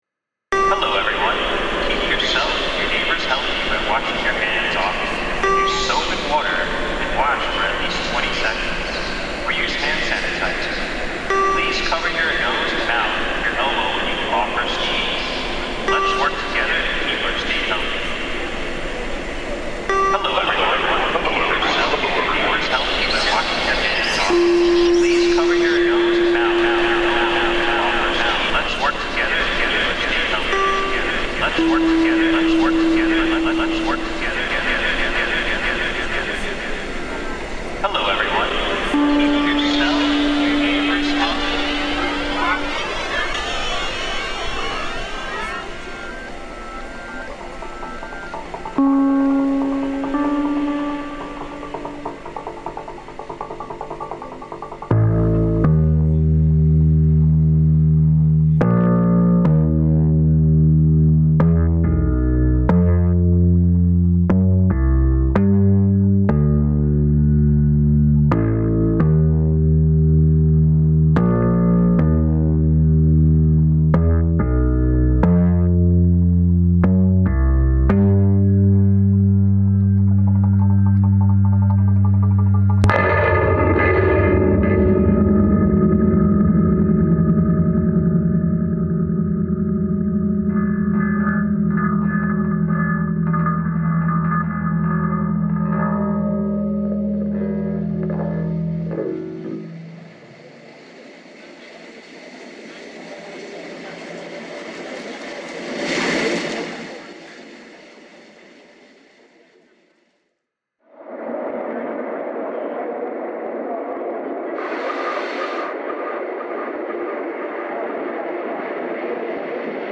Grand Central, New York lockdown sound reimagined